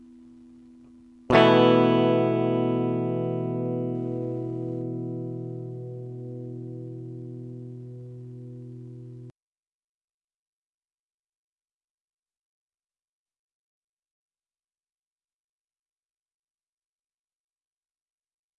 吉他 大调和弦 " A大调
描述：用我的Ibanez GAX70电吉他演奏的A大调和弦.
Tag: 一个和弦 一大调 吉他 吉他和弦